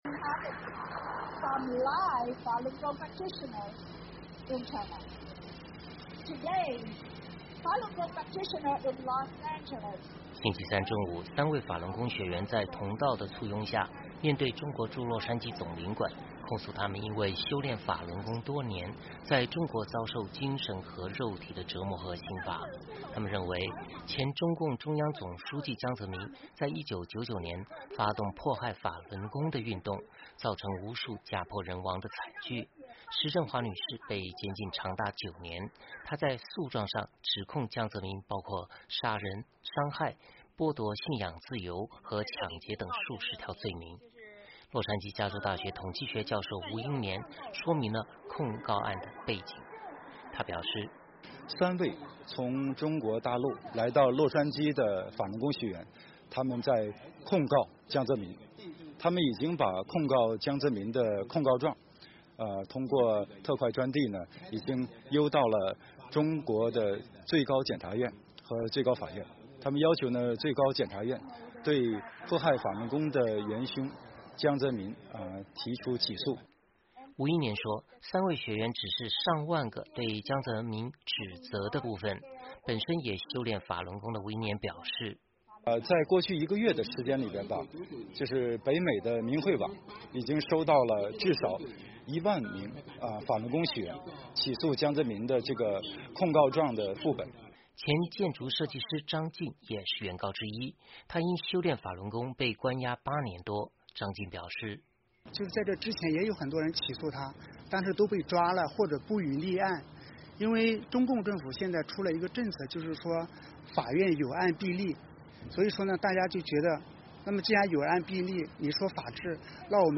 星期三中午，三位法轮功学员在同道的簇拥下，面对中国驻洛杉矶总领馆，控诉他们因为修炼法轮功多年在中国遭受精神和肉体的折磨和刑罚。他们认为前中共中央总书记江泽民在1999年发动迫害法轮功的运动，造成无数家破人亡的惨剧。